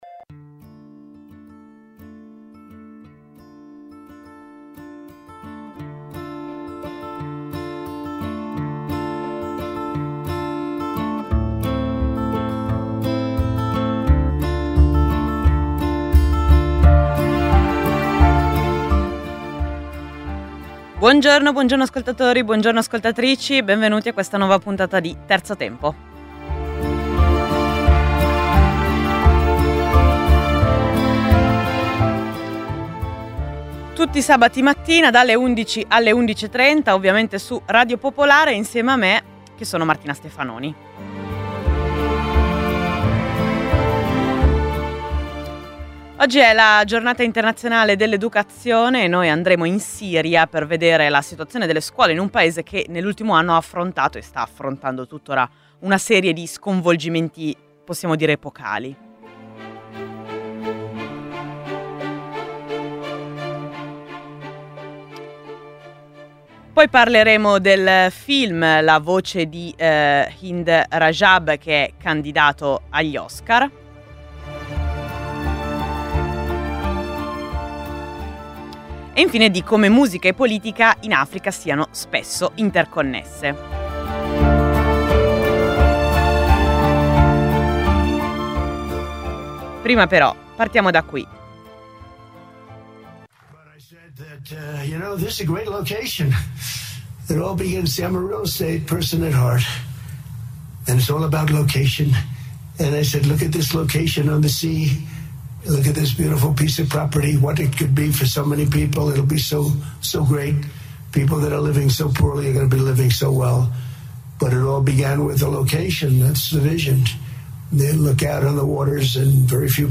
Sarà una mezz’ora più rilassata rispetto all’appuntamento quotidiano, ricca di storie e racconti, ma anche di musica.